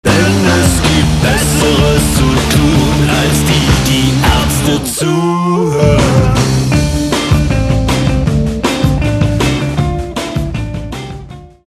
GenrePunkrock